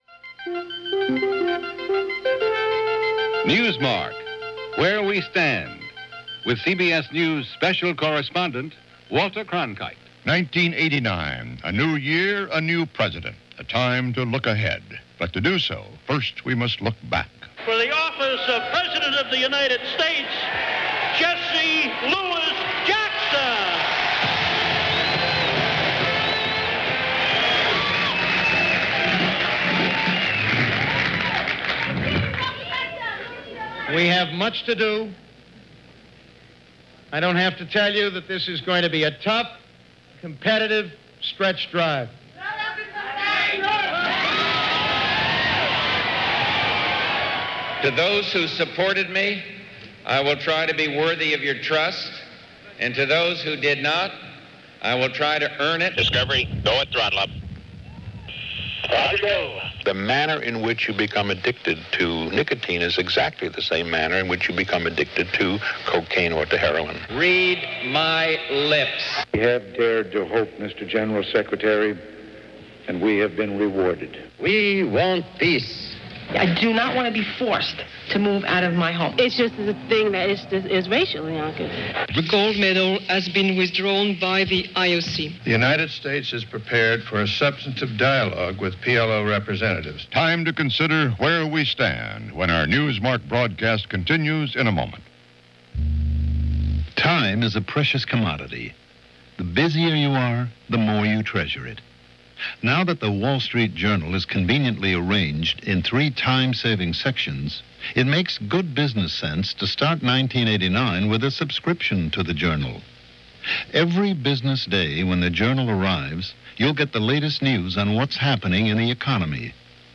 January 1, 1989 – CBS Radio – Where We Stand 1989 – Hourly News – Gordon Skene Sound Collection –